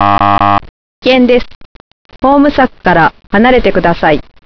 柵から離れるよう警告する音声